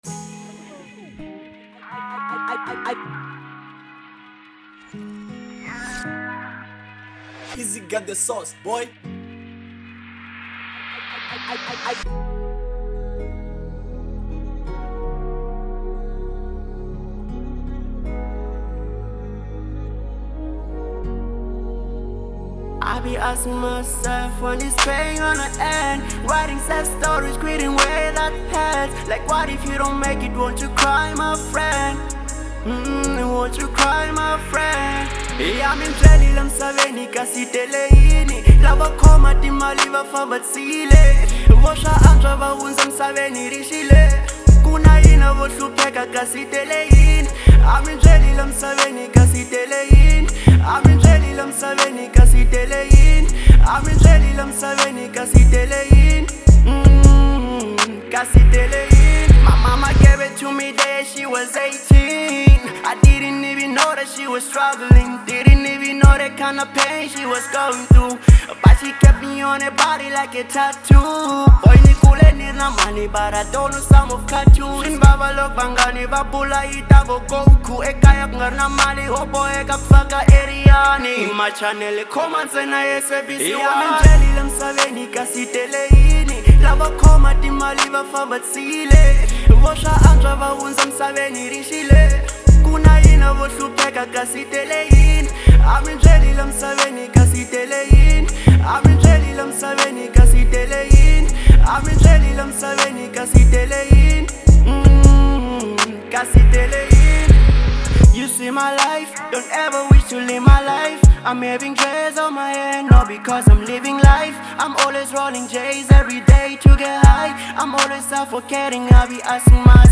03:09 Genre : Trap Size